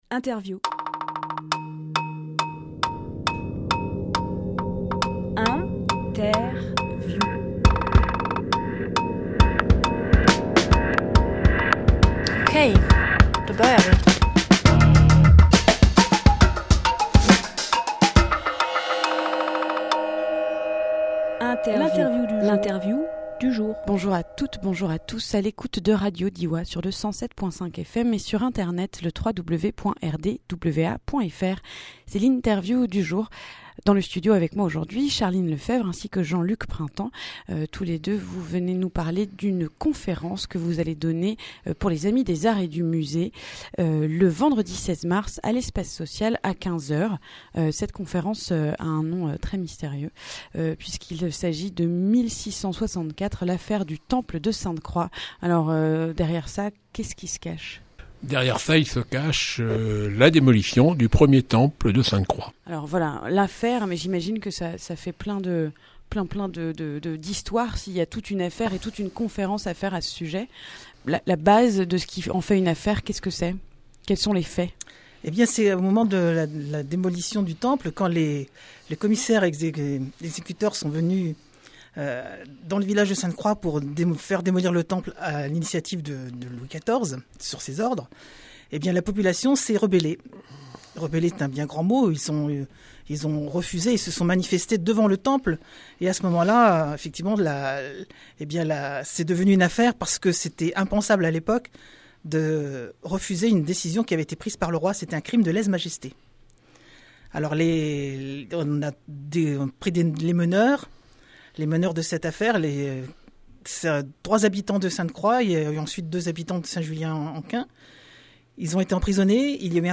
Emission - Interview Conférence : l’affaire du Temple de Sainte-Croix Publié le 9 mars 2018 Partager sur…
28.02.18 Lieu : Studio RDWA Durée